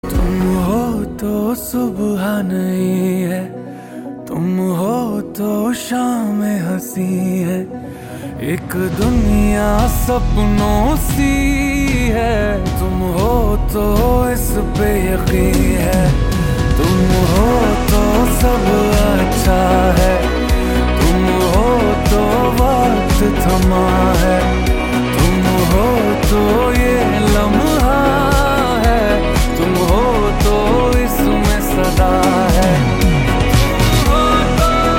romantic.